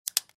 Звуки револьвера
Звук взвода курка револьвера